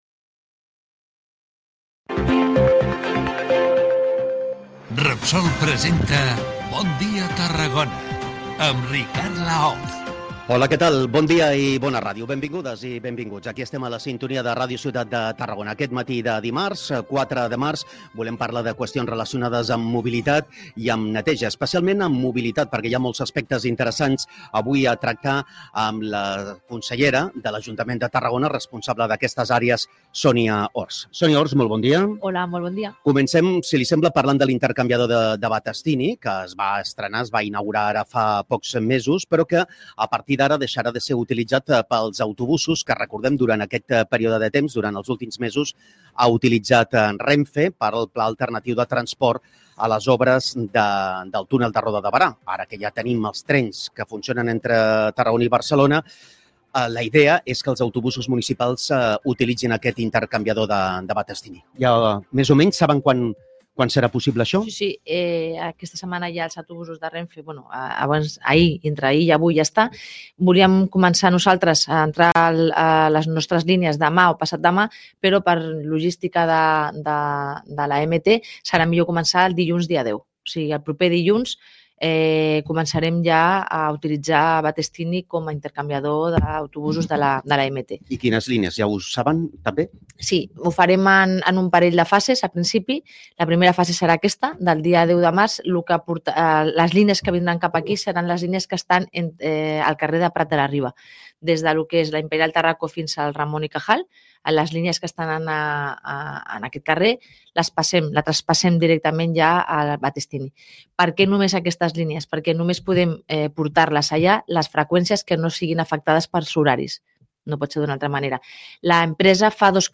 Sonia Orts, consellera de Mobilitat i Neteja de l’Ajuntament de Tarragona, anuncia al ‘Bon dia Tarragona’ que l’intercanviador de Battestini començarà a rebre autobusos municipals el proper dilluns 10 de març. Orts detalla a l’entrevista quines seran les primeres línies de bus que passaran per l’intercanviador i quines hi aniran a partir de l’estiu. També comenta les mesures aplicades per resoldre el problema d’aparcament a la Part Alta i anuncia possibles noves mesures per al pàrquing de Torroja.